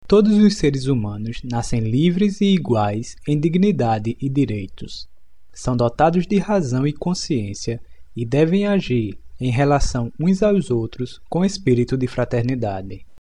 Sample text in Brazilian Portuguese